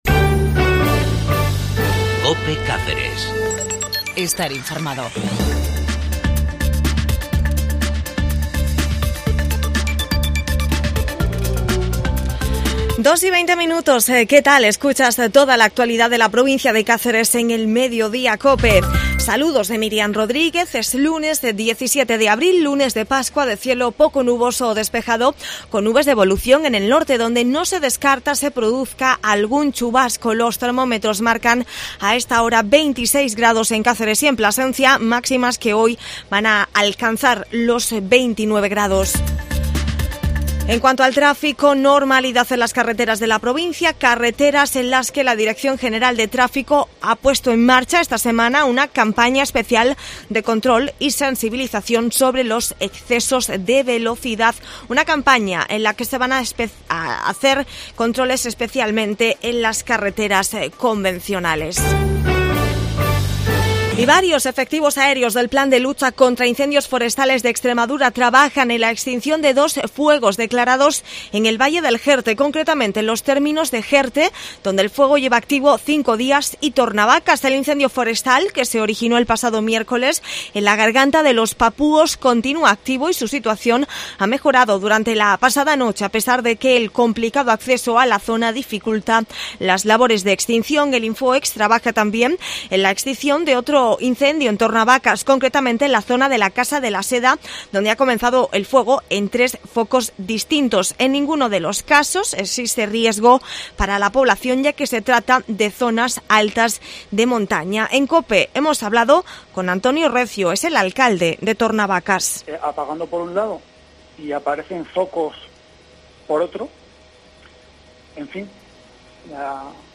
AUDIO: INFORMATIVO LOCAL 17 DE ABRIL EN CÁCERES